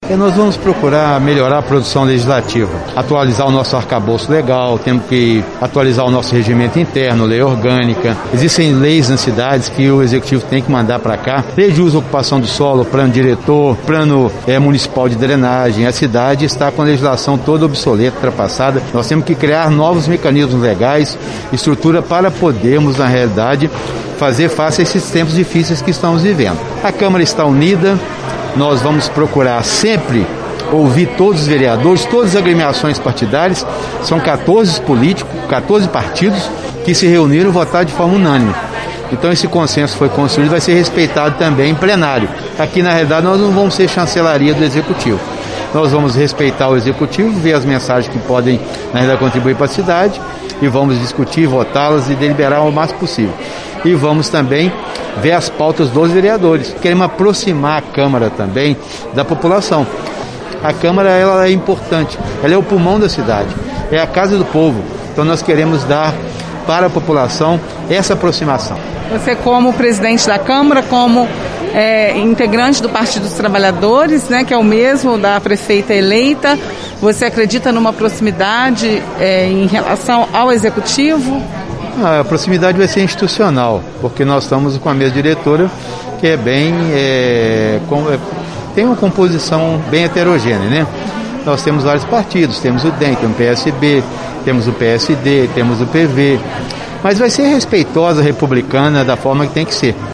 O presidente da Câmara, Juracy Scheffer falou sobre a expectativa para os trabalhos.